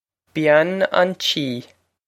Bean an Tí Byan on chee
Byan on chee
This is an approximate phonetic pronunciation of the phrase.